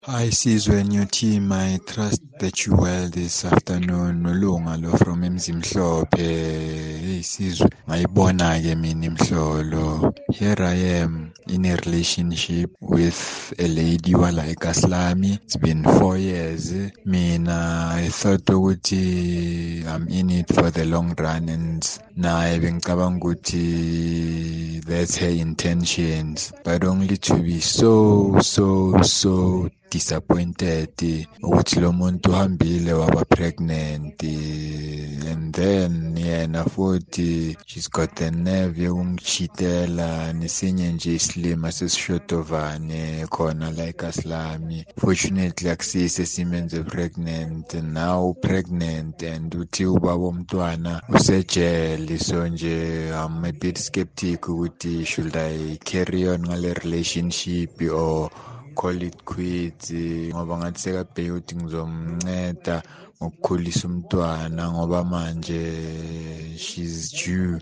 Kaya Drive listeners weigh in on dead-end relationships: